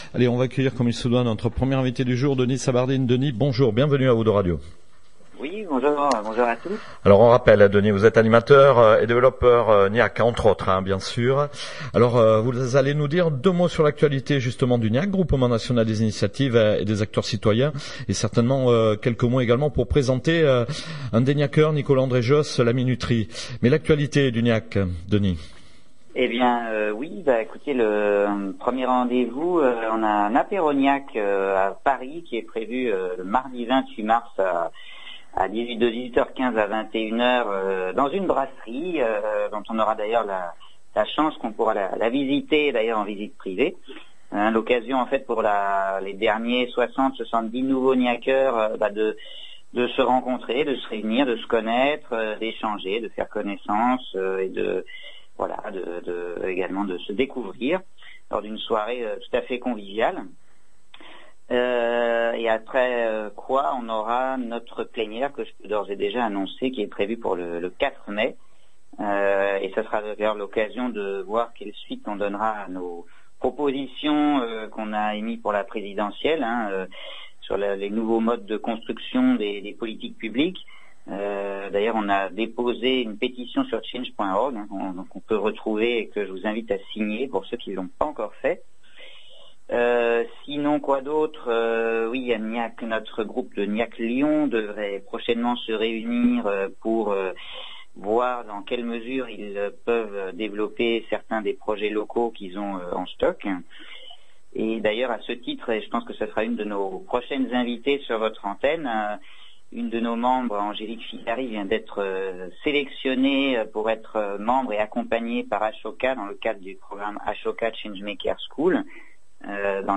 INTERVIEW ICI